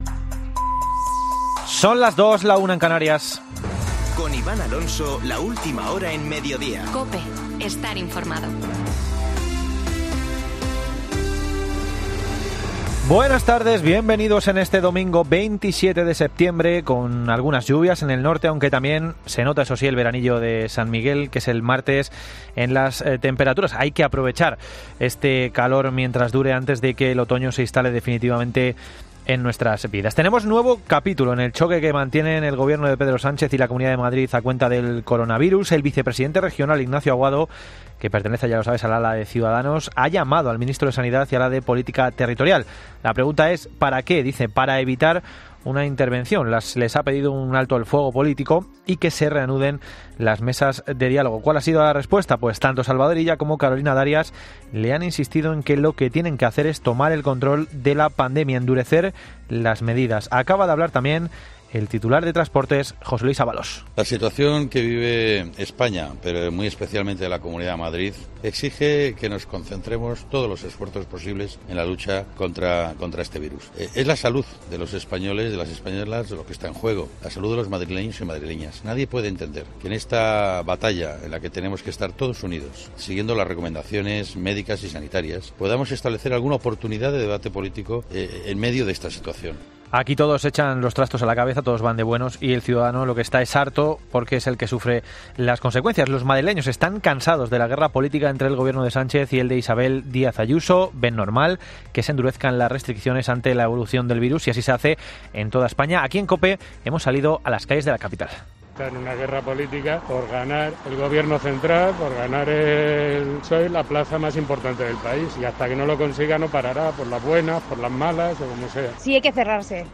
Boletín de noticias COPE del 27 de septiembre de 2020 a las 14.00 horas